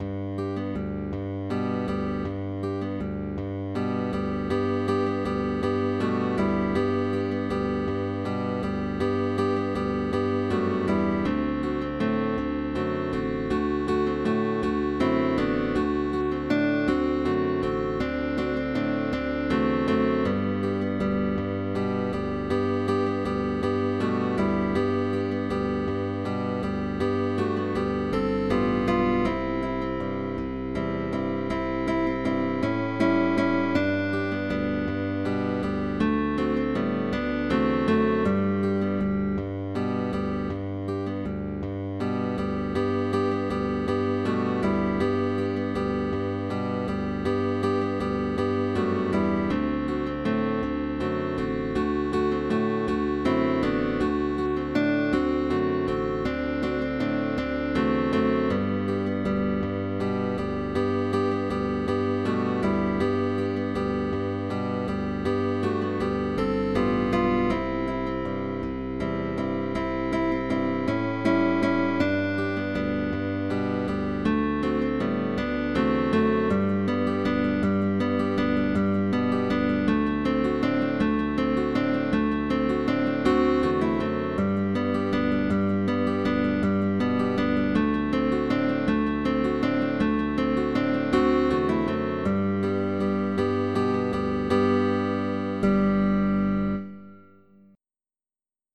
Valid for guitar orchestra, with optional bass.